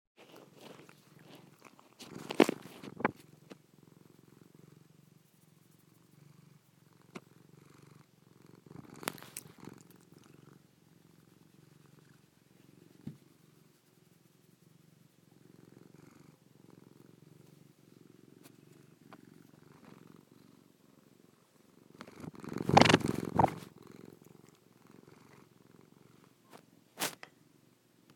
My cat purring